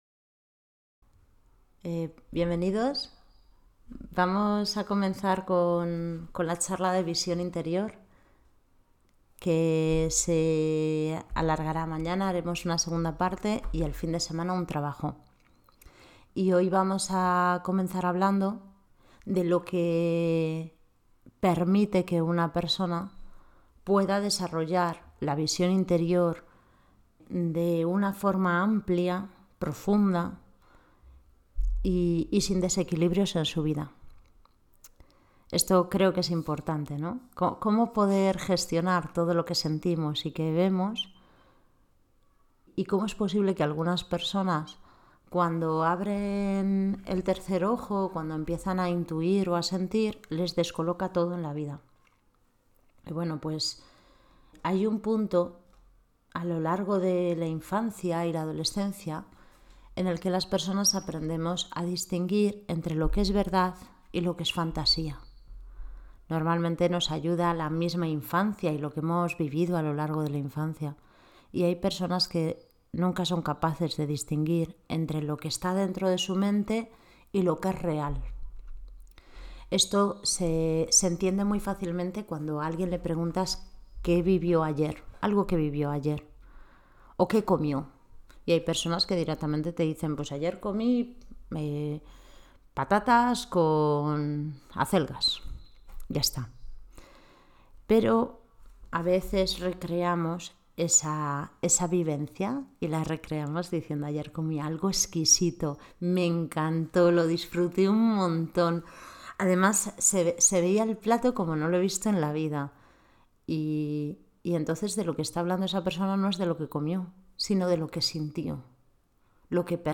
Charla encuentro sobre la Visión Interior